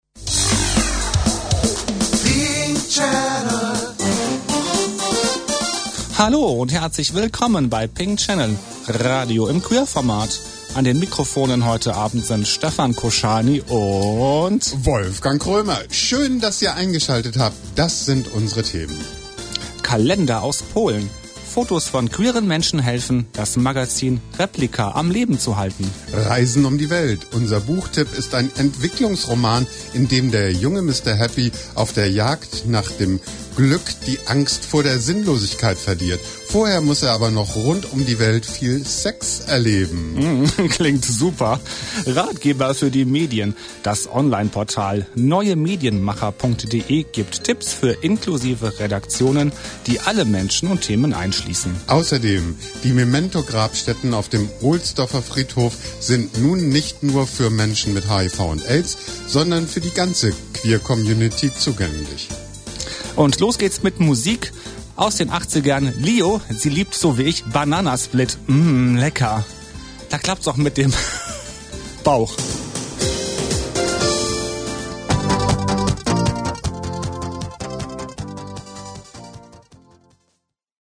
Sendung